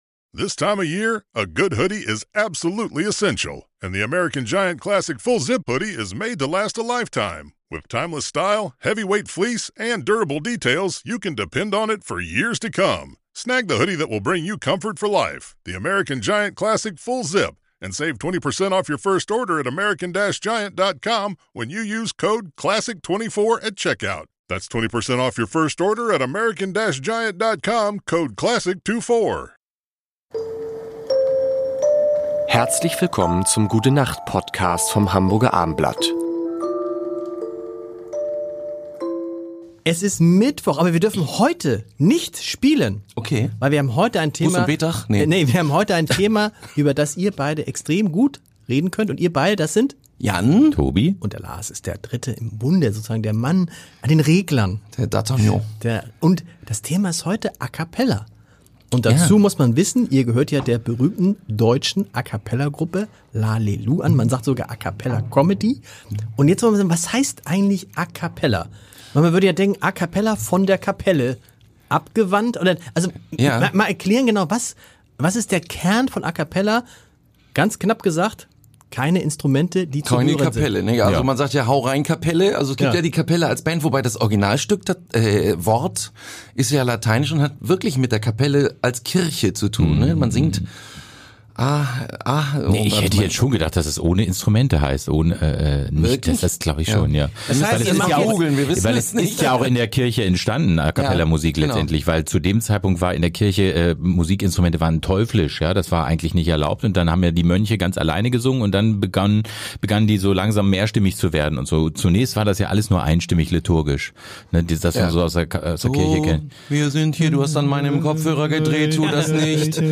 Sie erwarten unterhaltsame, nachdenkliche
und natürlich sehr musikalische fünf Minuten. Es wird gesungen,
gelacht und philosophiert, und Stargäste sind auch dabei.